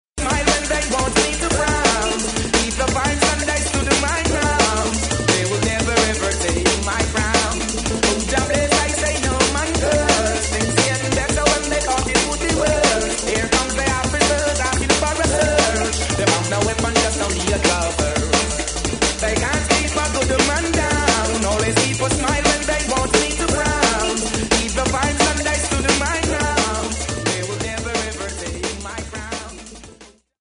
TOP > Vocal Track